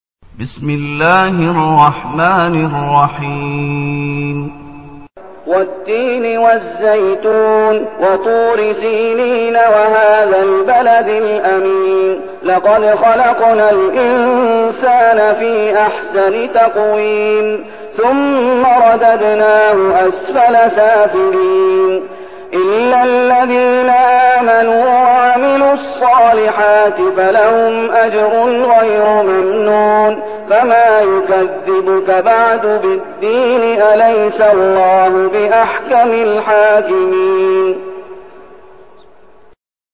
المكان: المسجد النبوي الشيخ: فضيلة الشيخ محمد أيوب فضيلة الشيخ محمد أيوب التين